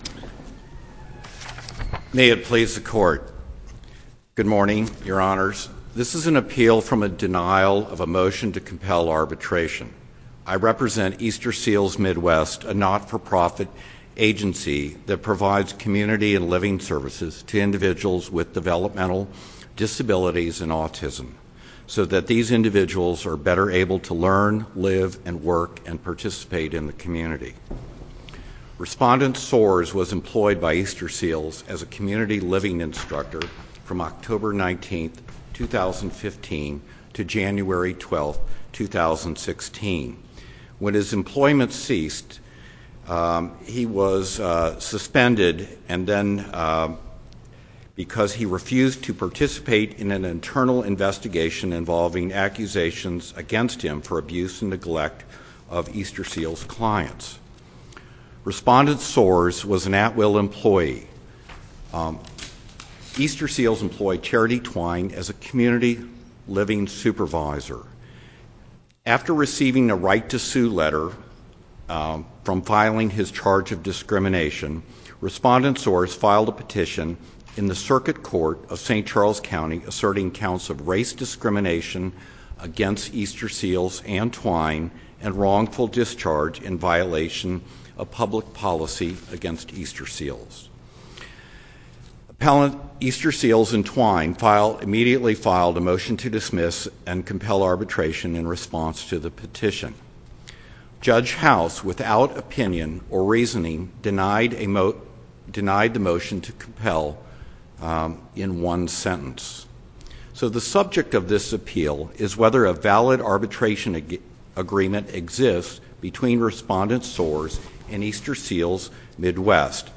link to MP3 audio file of oral arguments in SC97018
SUPREME COURT OF MISSOURI